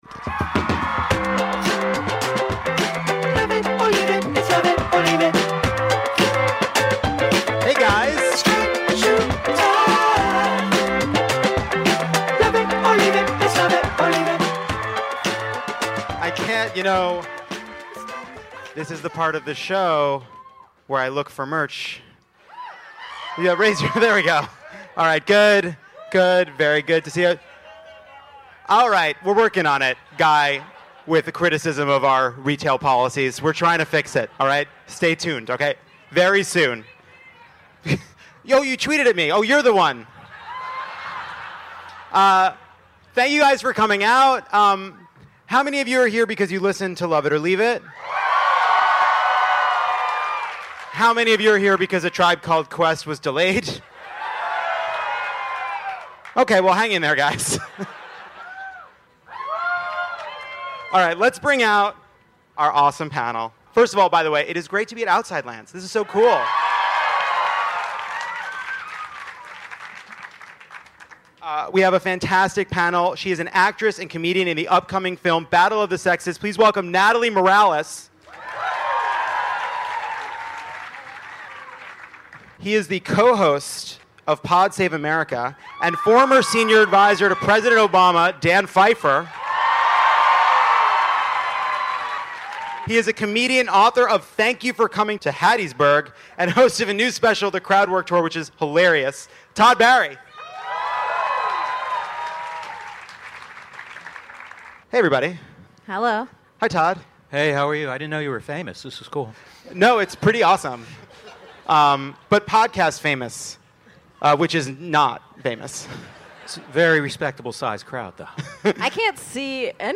Todd Barry, Natalie Morales, and Dan Pfeiffer join Jon to discuss North Korea, the opioid crisis, McConnell v. Trump, immigration, and the rest of it. Recorded live at the Outside Lands Festival in SF.